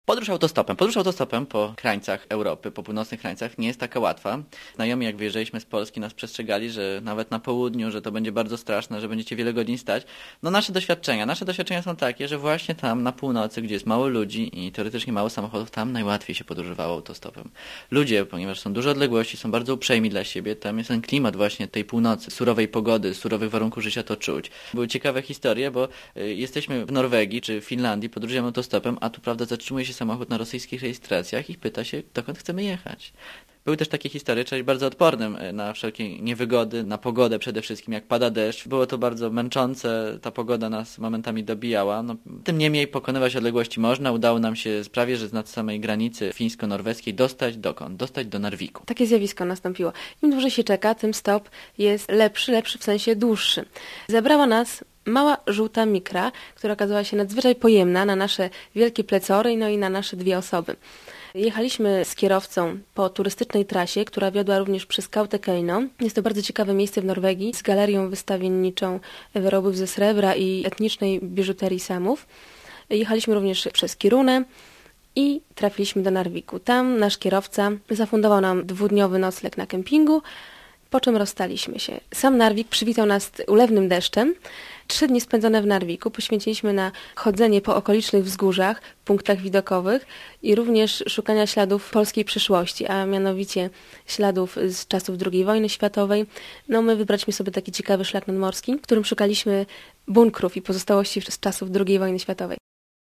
Tematy radiowych opowieści: "Droga przez Rosję", "Witaj Norwegio!", "Wypad do Finlandii", "Autostopem po północy", "Na lofotach", "Trondheim", "Parki narodowe", "Droga Trolli", "Drewniane kościoły", "Skandynawskie trofea", "Raj dla autostopowiczów" oraz "Szkoła przetrwania".